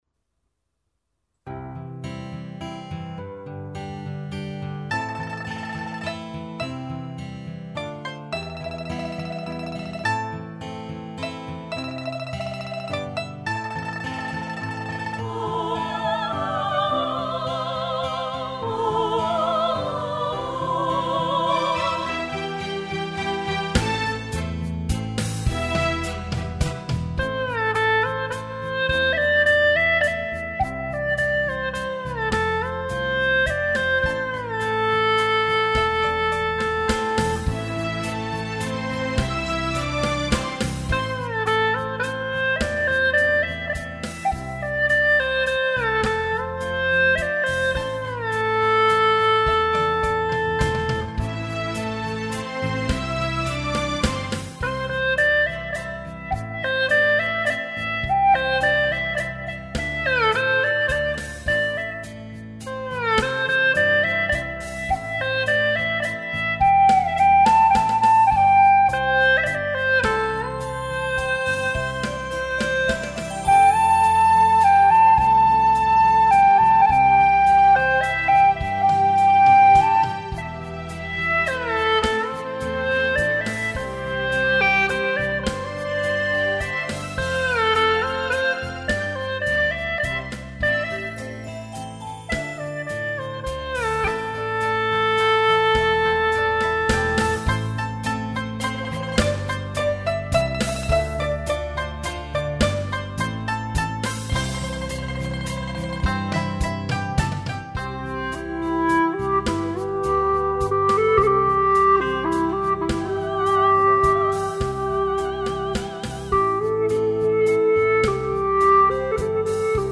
调式 : C
柔情似水,太美啦陶醉
婉转柔美,犹如天籁。
旋律流畅,悠扬动听！